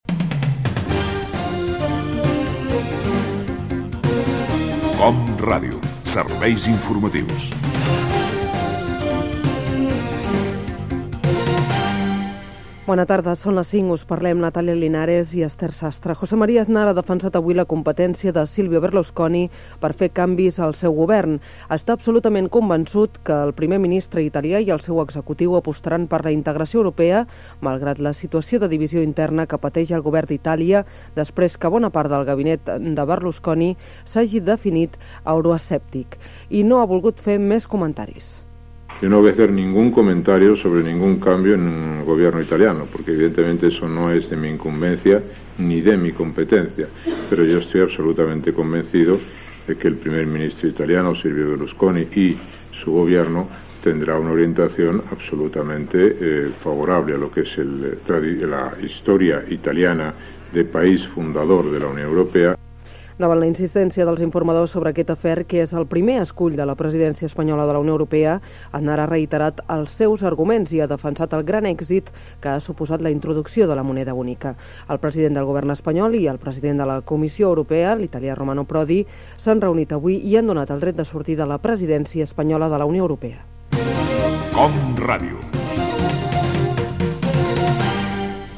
Careta del programa, el primer ministre Silvio Berlusconi, declaracions del predident espanyol José María Aznar. Indicatiu de l'emissora
Informatiu